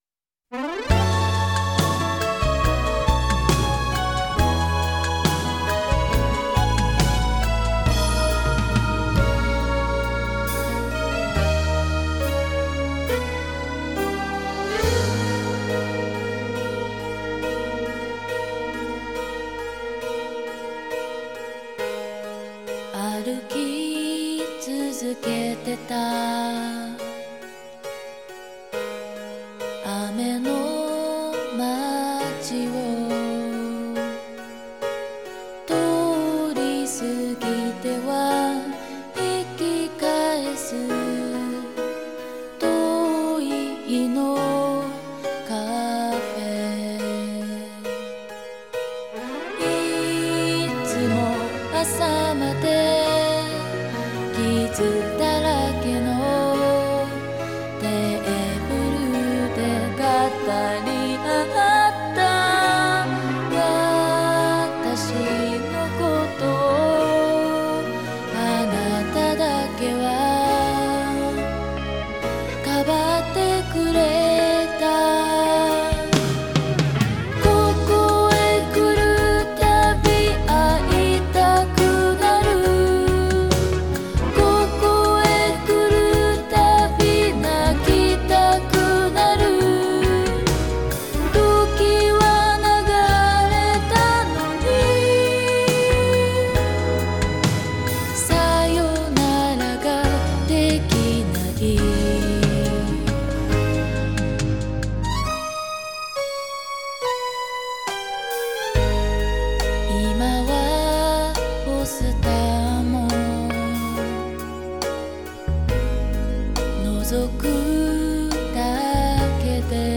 Genre: DOMESTIC(J-POPS).